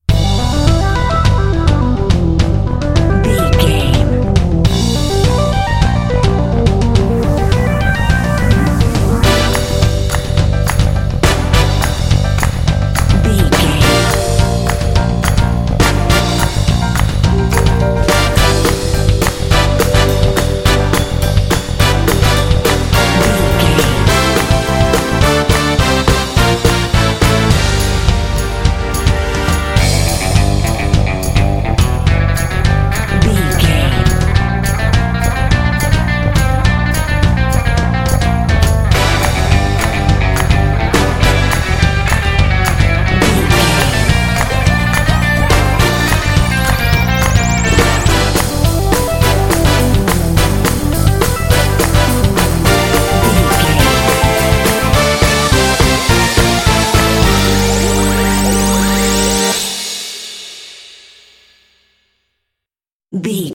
Aeolian/Minor
Fast
dark
groovy
funky
synthesiser
drums
bass guitar
brass
electric guitar
synth-pop
new wave